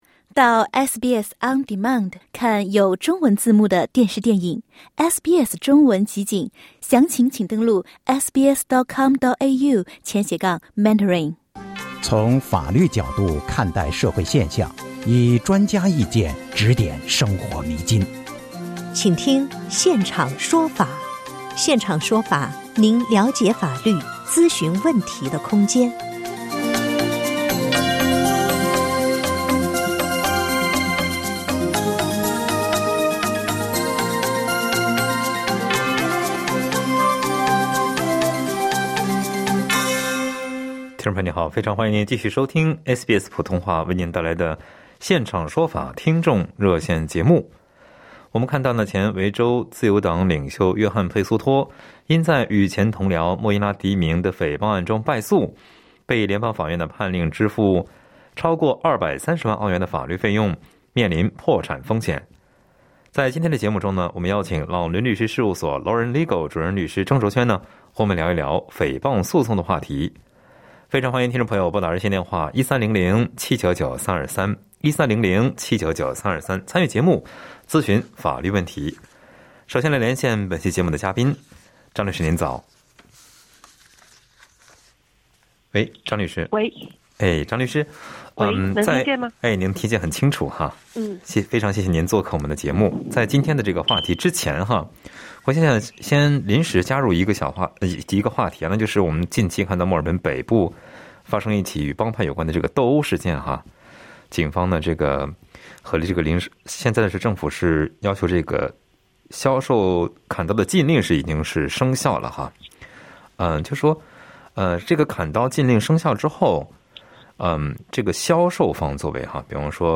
在SBS中文普通话《现场说法》节目中，澳洲律师为民众深入浅出地解读常见法律问题。